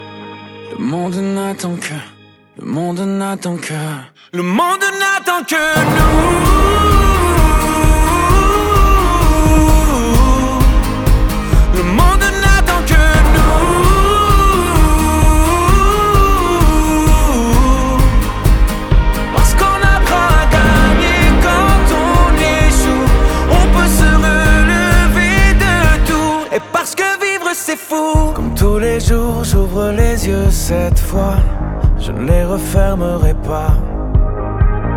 2025-06-06 Жанр: Поп музыка Длительность